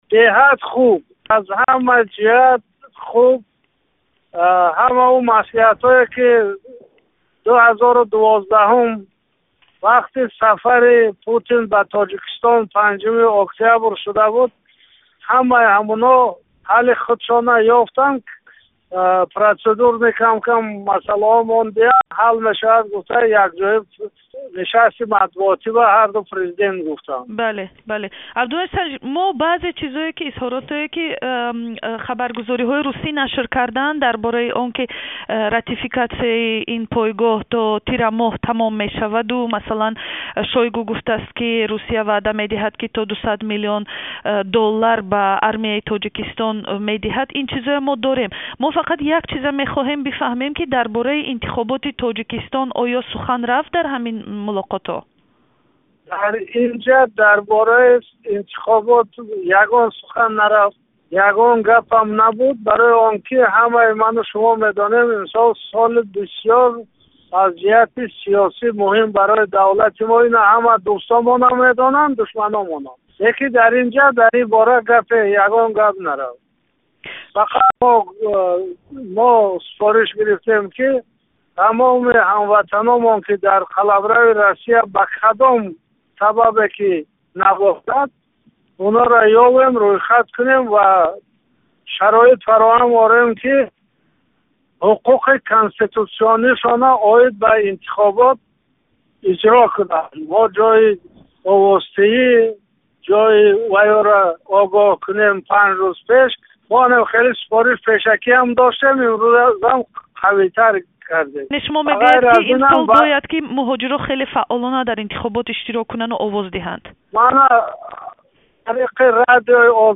Абдумаҷид Достиев-сафири Тоҷикистон дар Маскав дар як сӯҳбати ихтисосӣ бо радиои Озодӣ мулоқоти раҳбарони Тоҷикистону Русияро арзёбӣ кард.